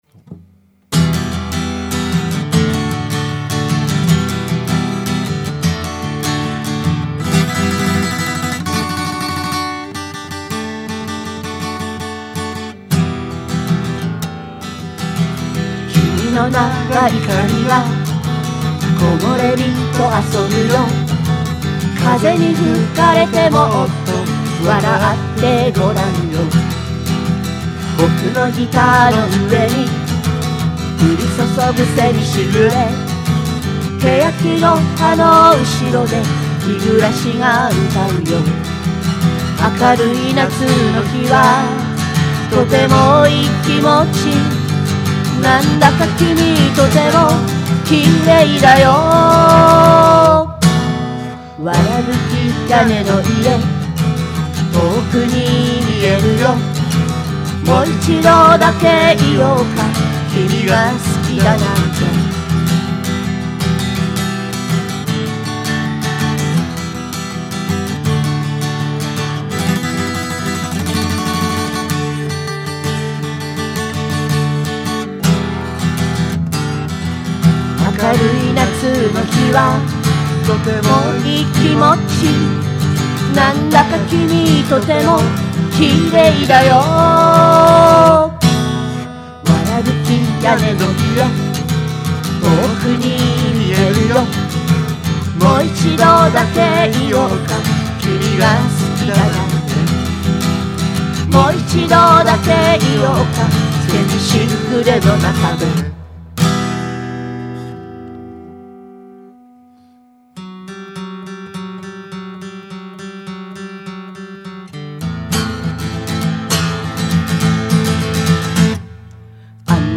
使用したギターはサンタクルーズのヴィンテージ・アーティスト、シュアの ＳＭ５７によるマイク録音。